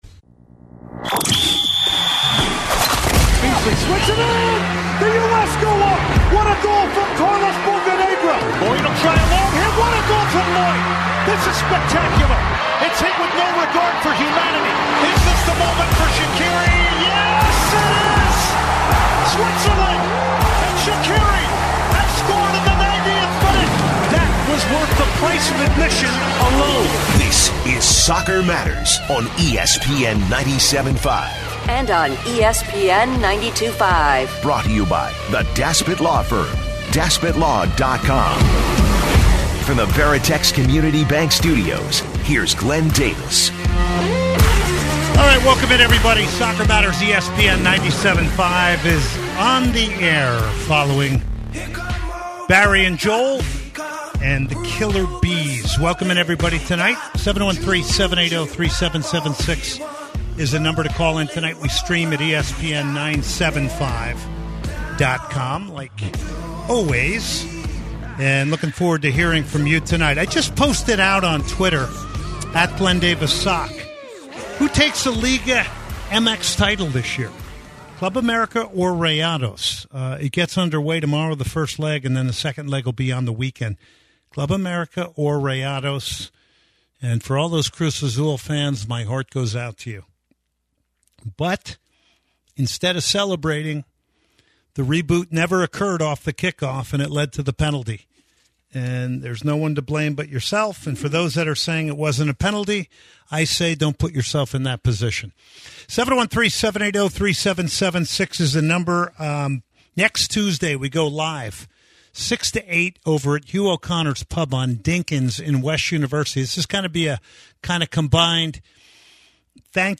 Calls about the corruption inside FIFA, and Saudi Arabia getting the 2034 World Cup
live from the MLS combine
conversation